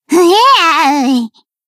BA_V_Ui_Swimsuit_Battle_Damage_1.ogg